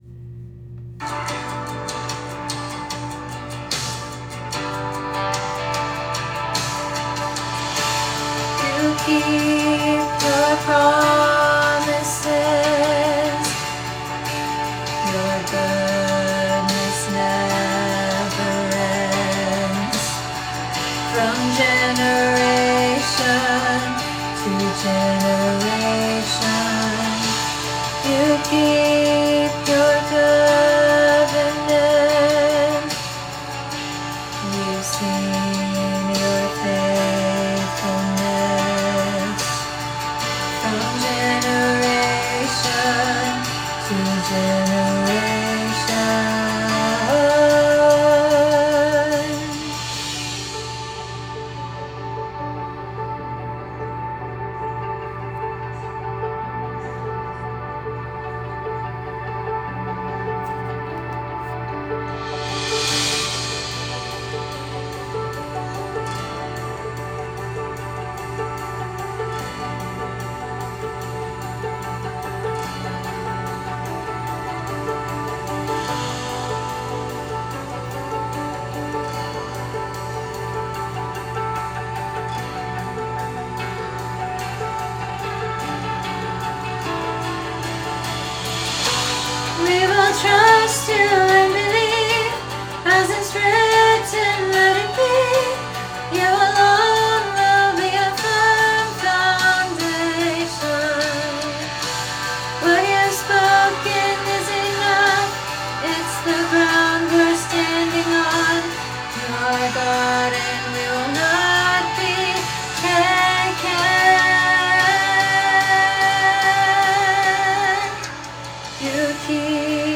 Lyric Sheet, Sheet Music & Vocal Rehearsal Tracks
The audio quality may be a bit raw but it will be sufficient for you to practice on your own.
Male Tenor Track